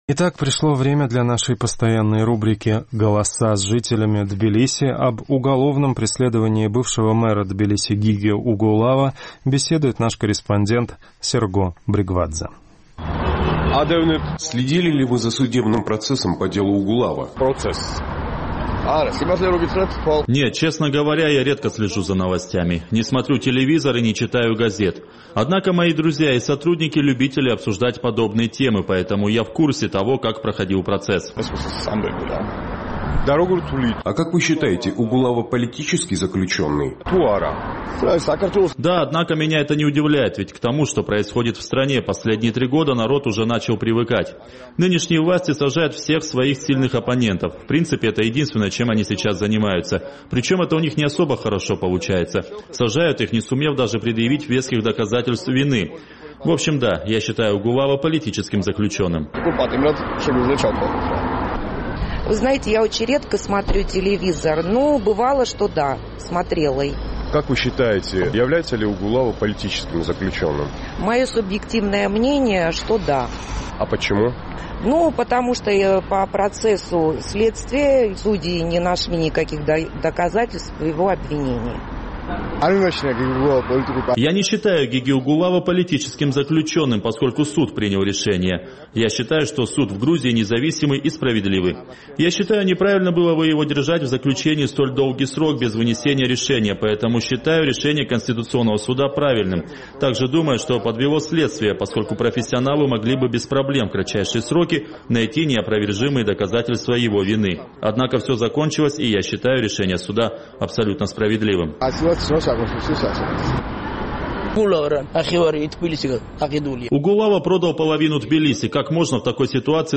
Представители «Нацдвижения» утверждают, что суд принял политическое решение по делу экс-мэра Тбилиси Гиги Угулава. Наш тбилисский корреспондент поинтересовался мнением жителей грузинской столицы по этому поводу.